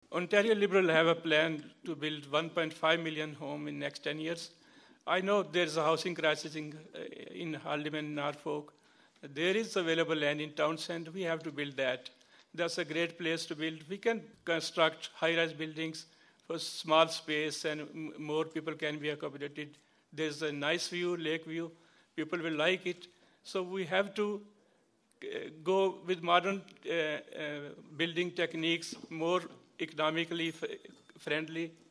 The Haldimand-Norfolk candidates met at the Royal Canadian Legion in Simcoe on Thursday night.